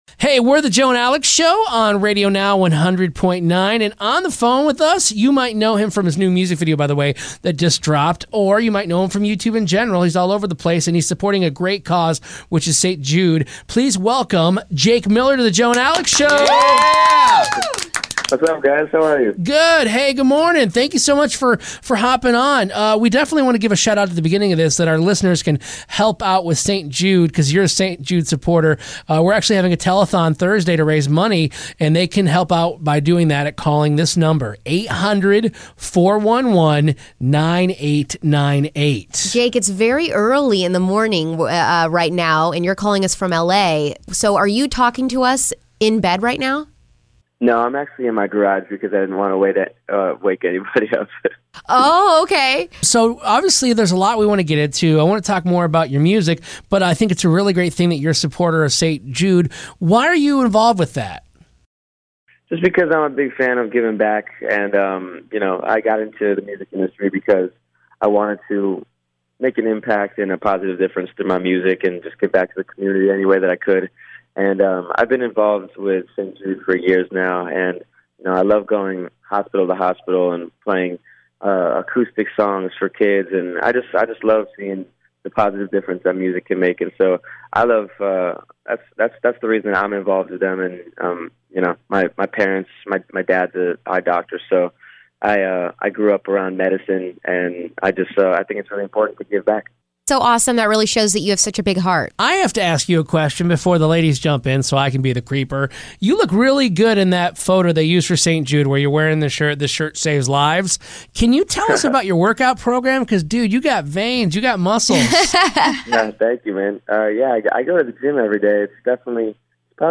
INTERVIEW: Jake Miller